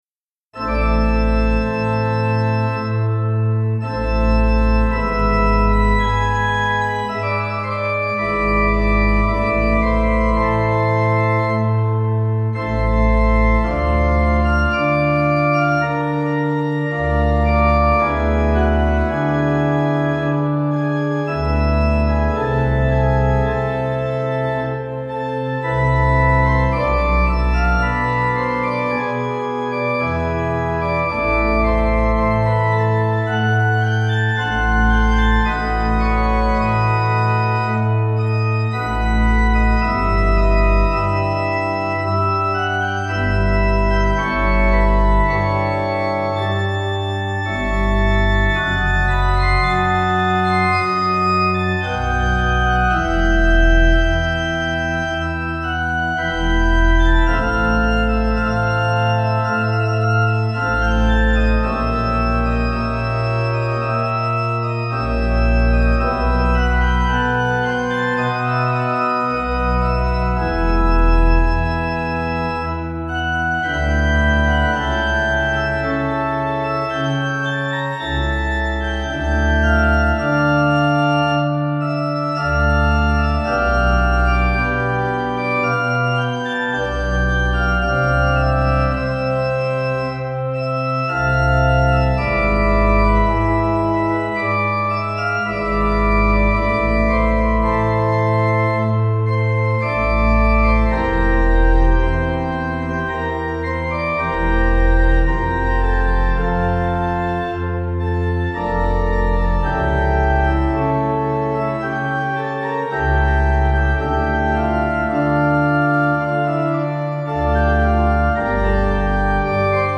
Easy Listening   C